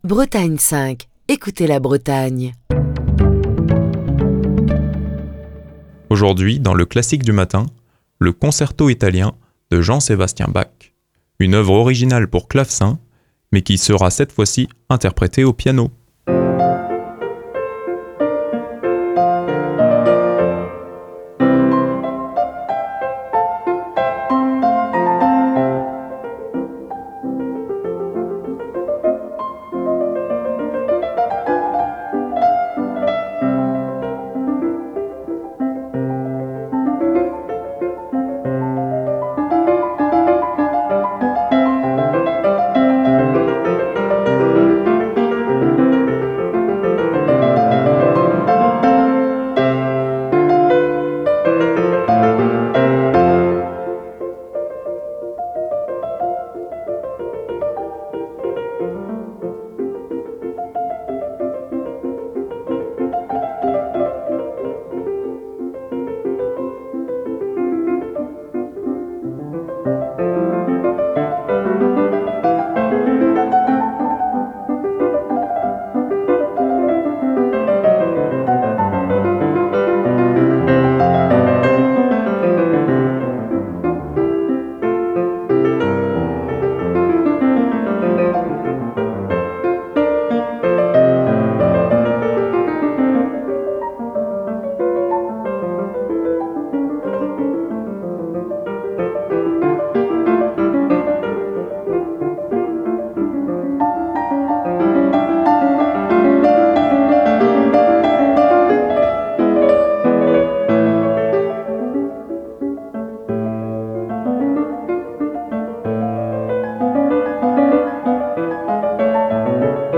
Ce jeudi au programme du Classique du matin, l'allegro du Concerto italien en Fa majeur de Jean Sébastien Bach, dans une interprétation d'Alfred Brendel.